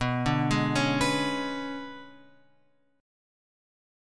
B Minor 6th Add 9th